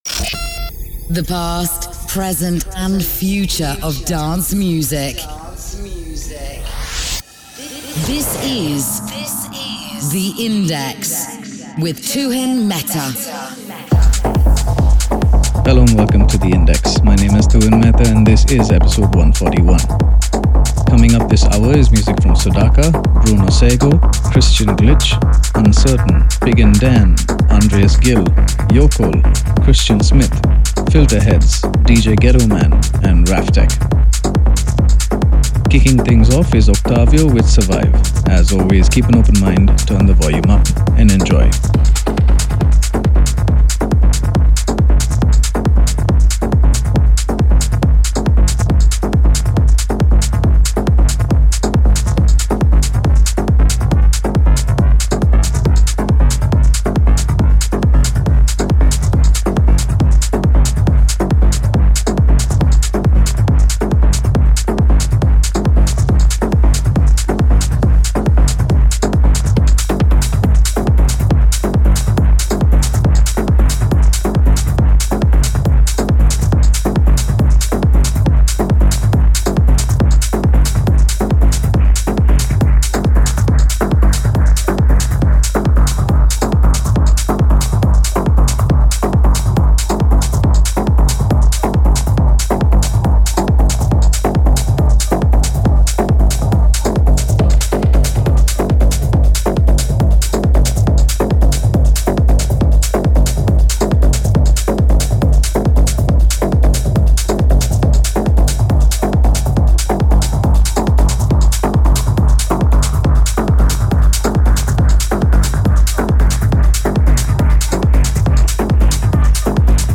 The past, present & future of dance music
Techno